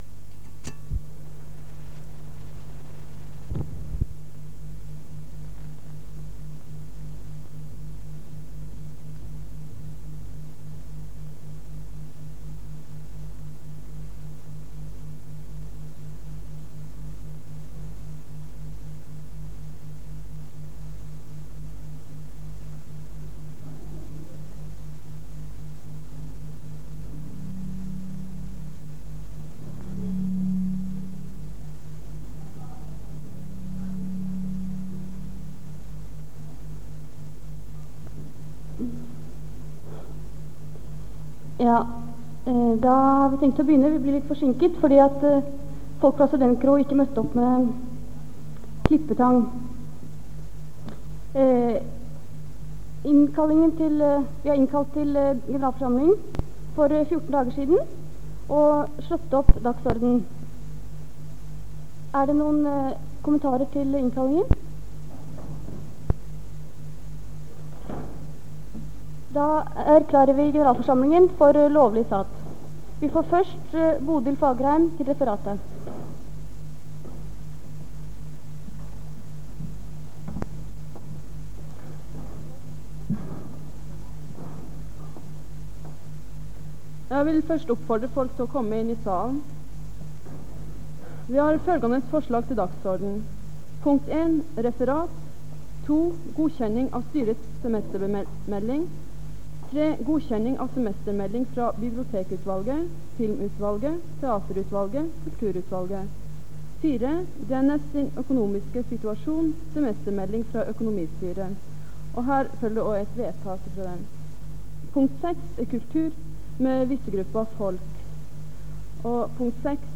Det Norske Studentersamfund, Generalforsamling, 24.11.1973